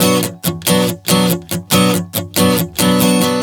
Strum 140 Bm 03.wav